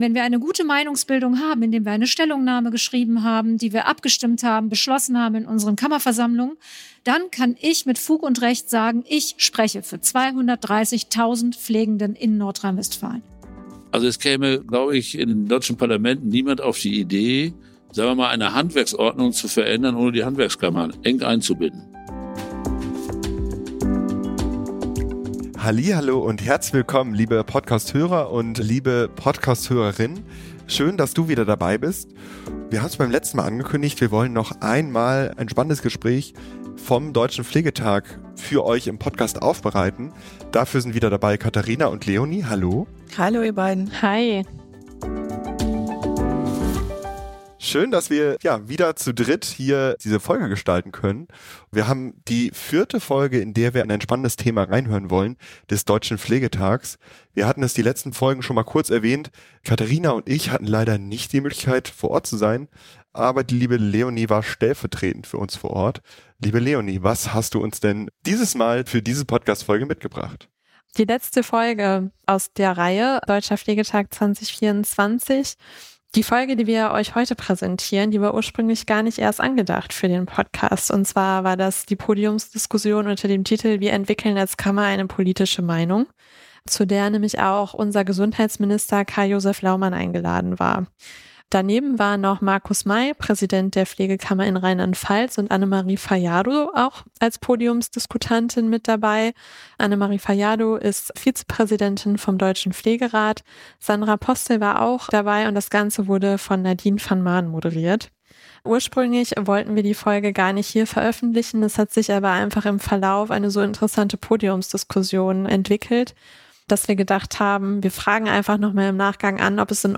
Beschreibung vor 1 Jahr Die Pflege präsentiert sich unter dem Motto „Pflege zeigt Haltung“ – aber wie kann sie auch politisch Einfluss nehmen bzw. aus diesem Motto echte Ergebnisse hervorbringen? Diese Frage steht im Mittelpunkt unserer letzten Sonderfolge vom Deutschen Pflegetag 2024.